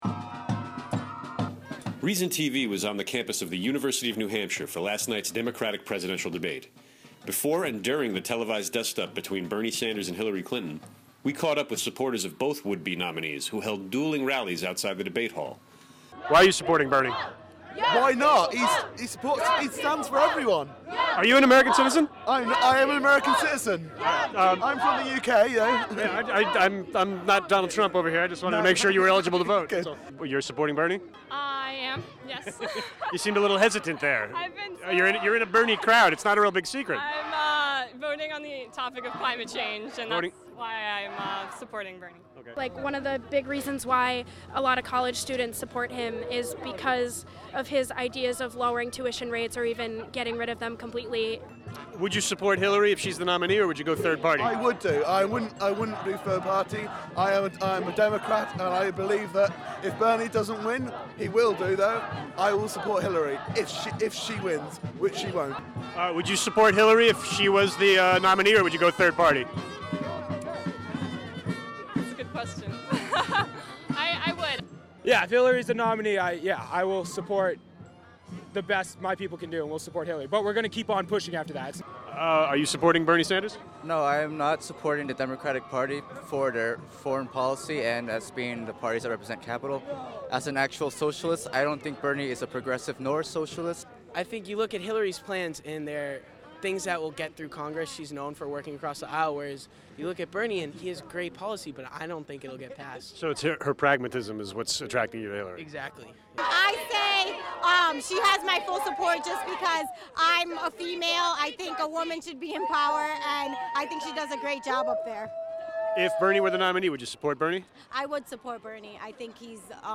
Before and during the televised dust-up between Bernie Sanders and Hillary Clinton, we caught up with supporters of both would-be nominees, who held dueling rallies outside the debate hall.